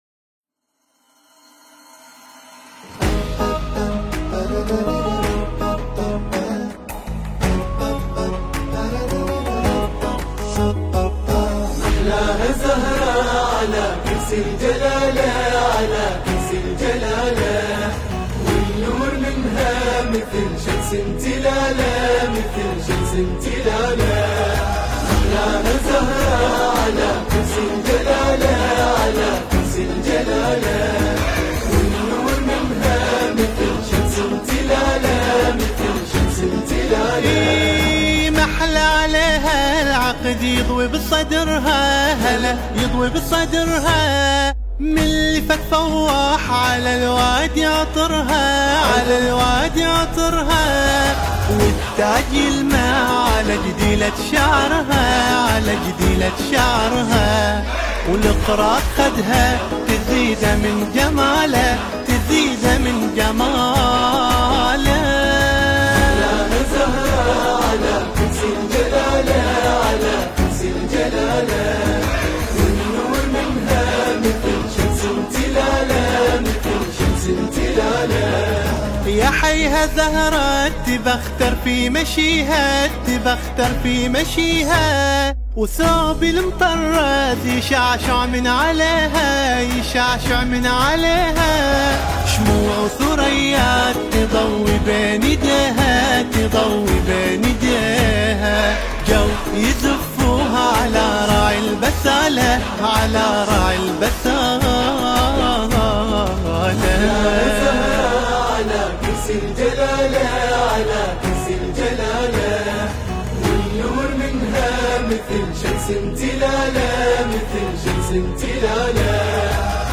أداء :
كلمات و ألحان : التراث القديم
الكورال :